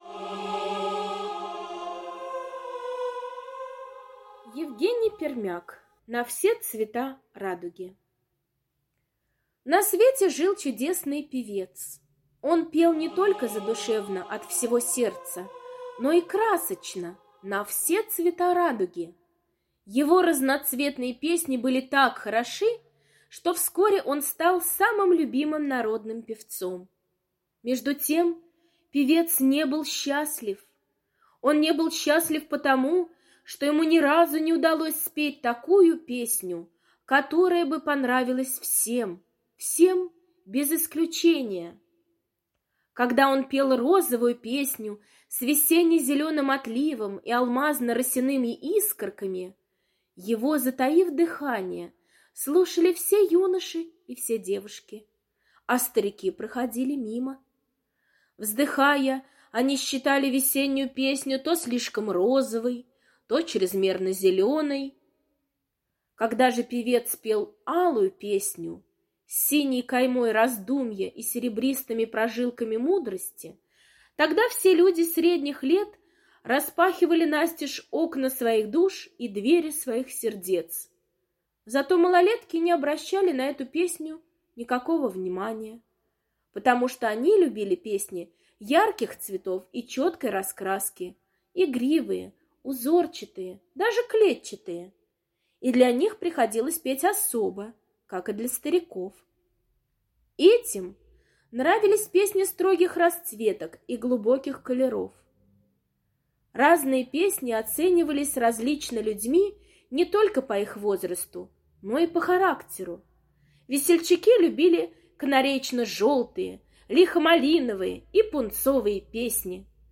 На все цвета радуги — аудиосказка Пермяка Е. История про чудесного певца, которого очень любил народ.